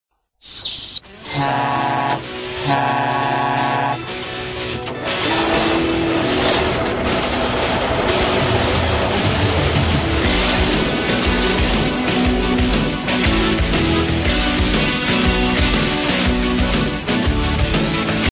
Générique de quelle émission TV ?